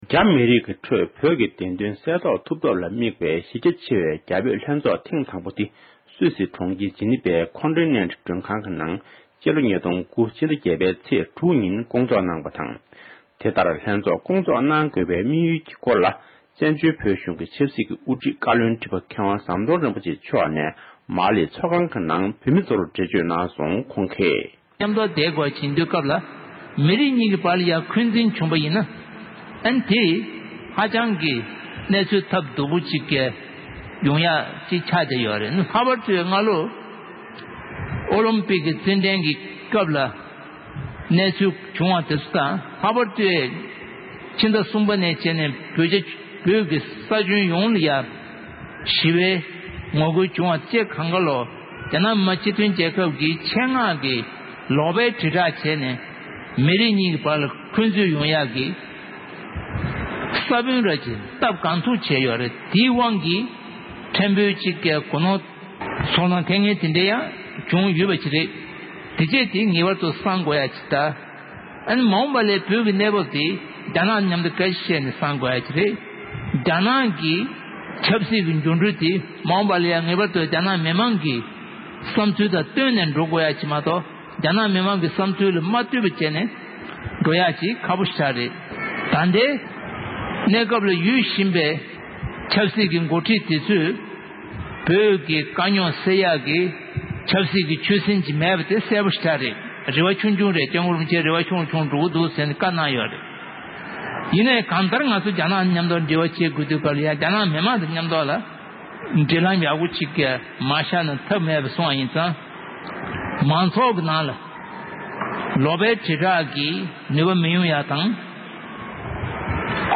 བཀའ་བློན་ཁྲི་པ་མཆོག་དྷ་སར་གསར་འགོད་གསལ་བསྒྲགས་ཤིག་གི་ཐོག་བཀའ་སློབ་གནང་བཞིན་པ།
སྒྲ་ལྡན་གསར་འགྱུར།